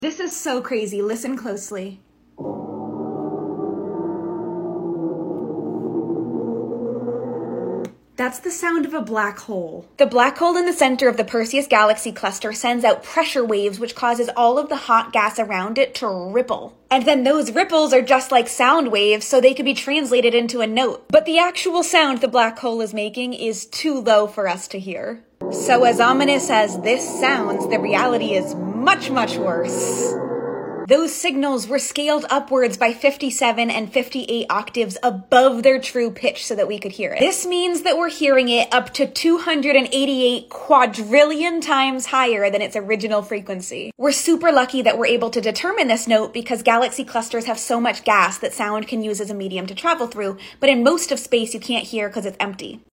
The SOUND of a black hole (kind of)!